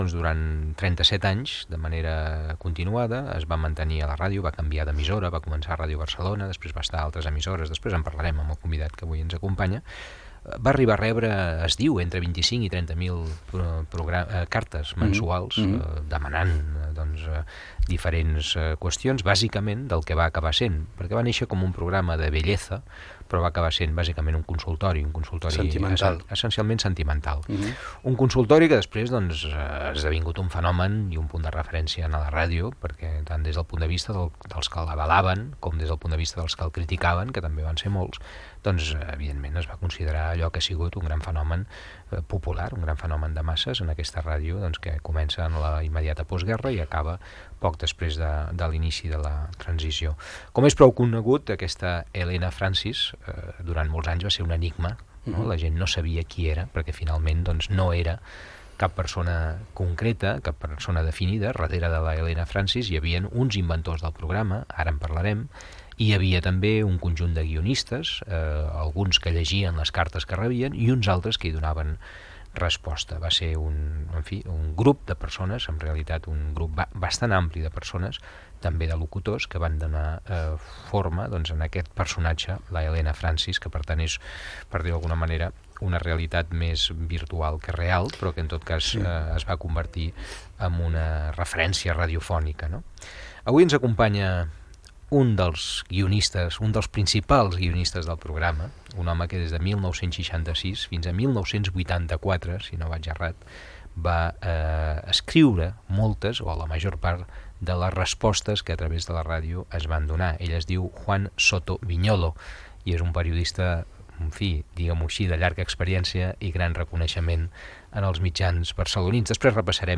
Fragment d'una entrevista
Gènere radiofònic Divulgació